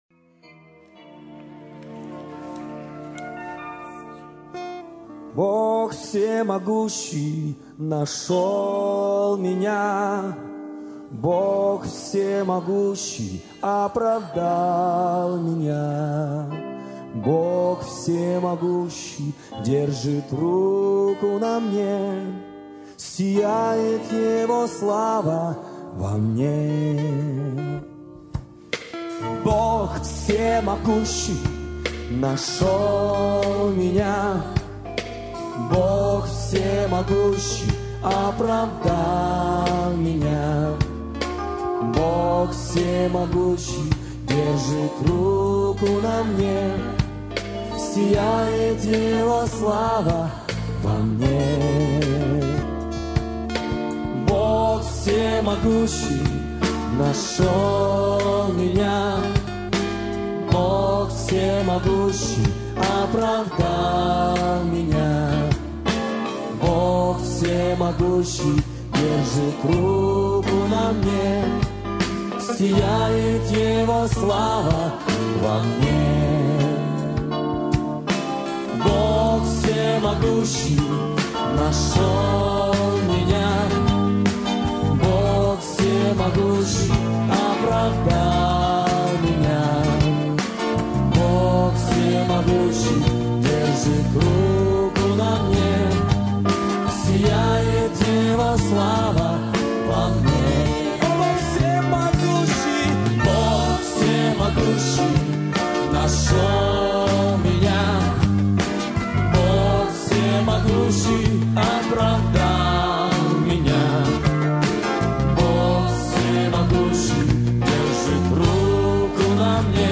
1610 просмотров 1256 прослушиваний 245 скачиваний BPM: 80